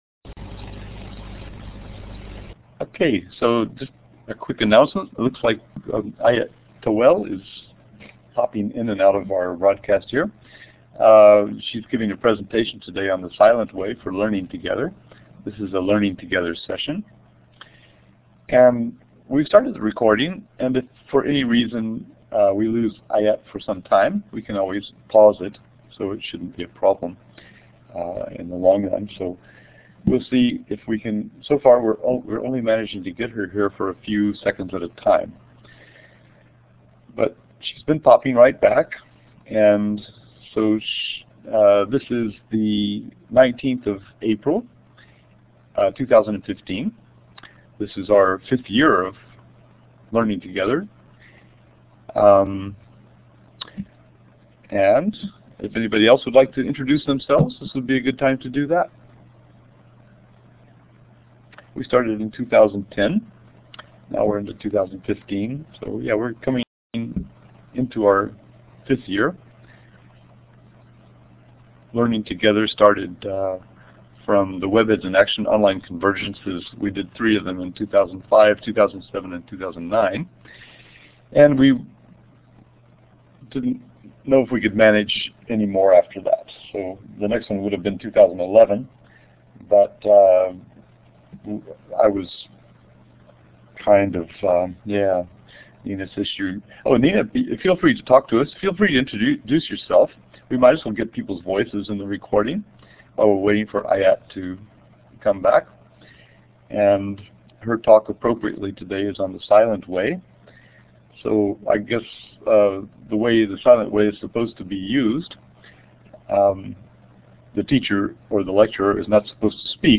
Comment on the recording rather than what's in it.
Where? Blackboard Collaborate (Elluminate)